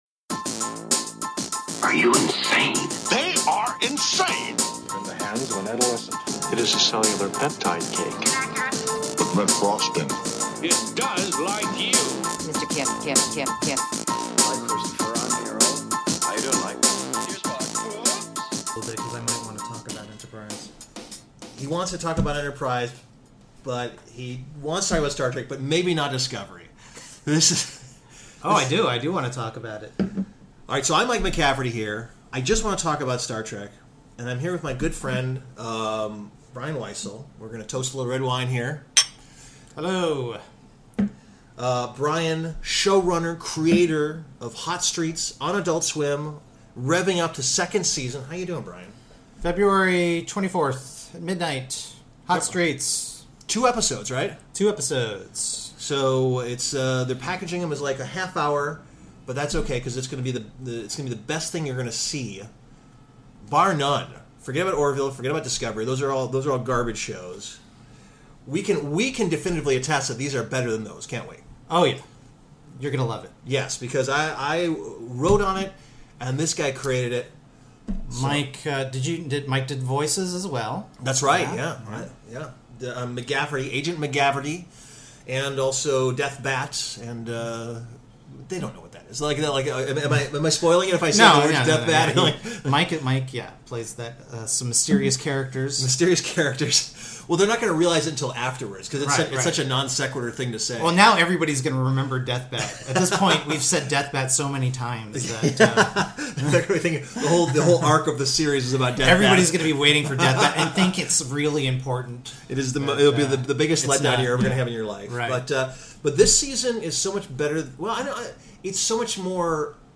Role play!